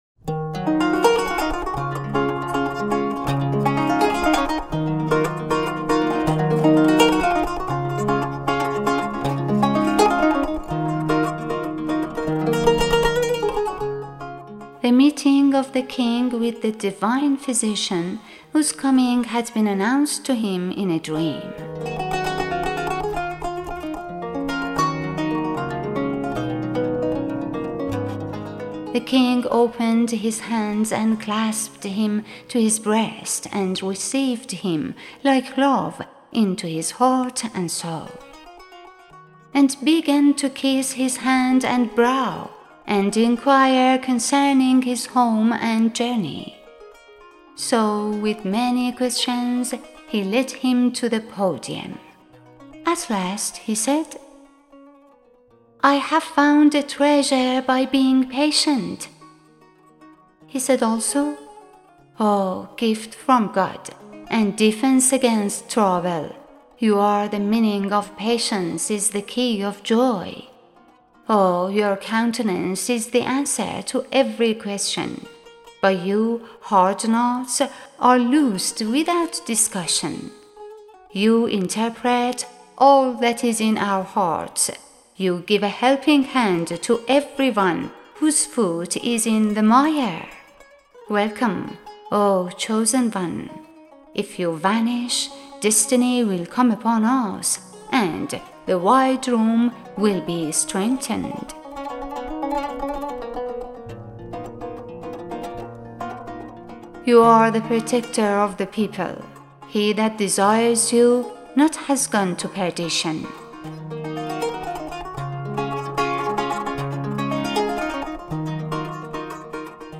Narrator and Producer